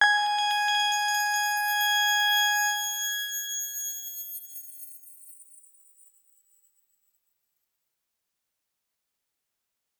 X_Grain-G#5-ff.wav